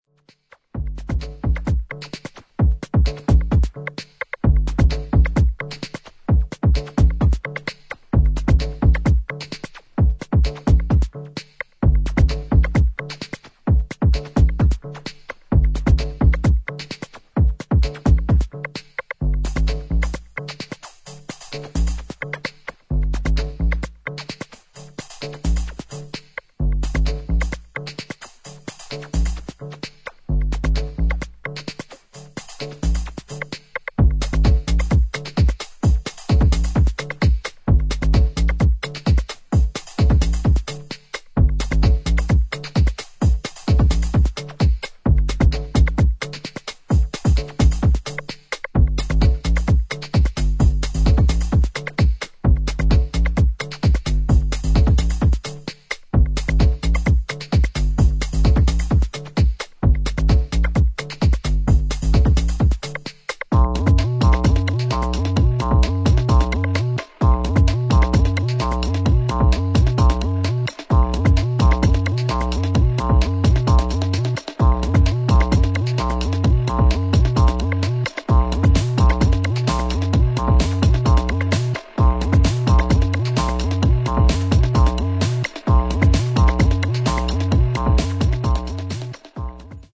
新旧どのテクノ・ハウスサウンドにも対応するであろう、円熟味を帯びた一枚です。